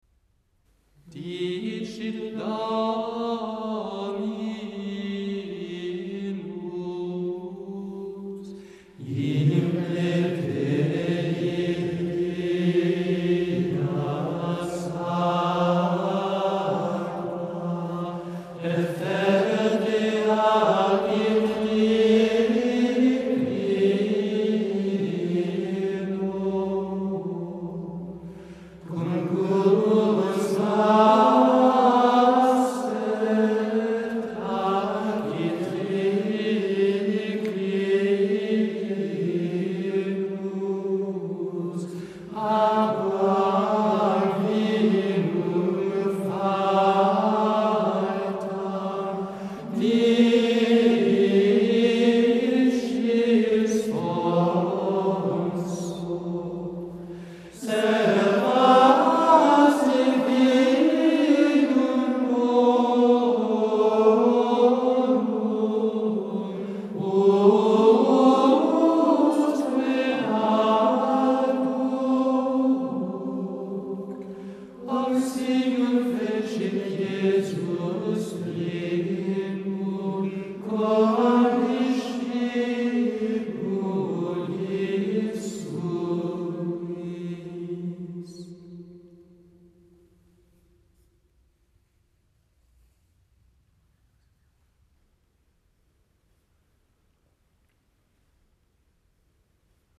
Commentaire spirituel
La mélodie de ce chant de communion est empruntée au 6e mode. Elle est très expressive et très nuancée, alliant dans ses brèves formules la simplicité, la solennité et une admiration qui éclate au beau milieu de la pièce, tout au long de la troisième des quatre phrases mélodiques qui la constituent.
L’intonation est déjà nuancée.
Il se dégage de ces quelques notes une vraie majesté, une grande noblesse.
La mélodie, toujours pleine d’autorité, souligne par son élan délicatement joyeux l’assurance de Jésus.